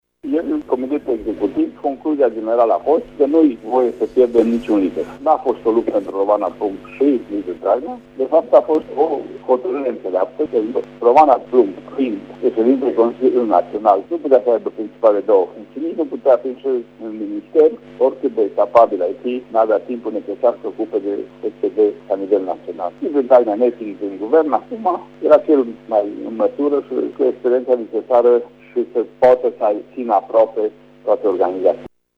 El a declarat, pentru Radio Tîrgu-Mureș, că în Comitetul Executiv de ieri nu s-a dat o bătălie între Rovana Plumb și Liviu Dragnea.